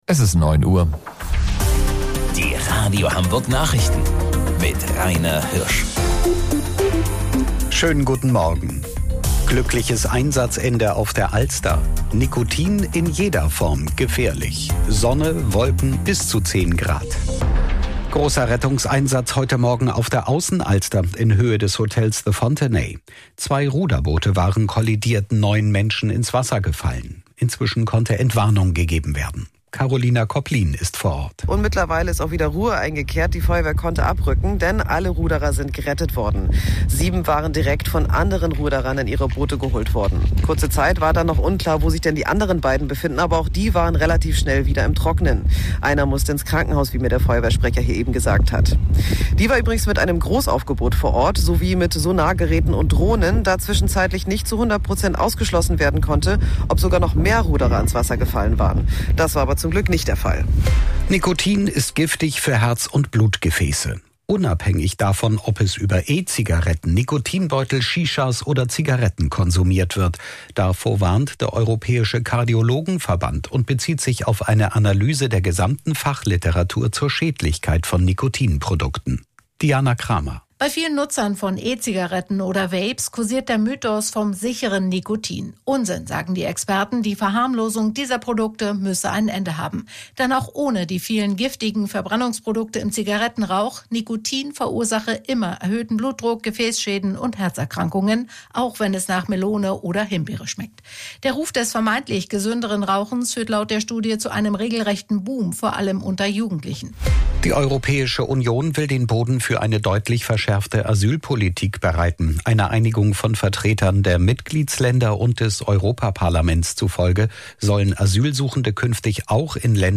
Radio Hamburg Nachrichten vom 18.12.2025 um 09 Uhr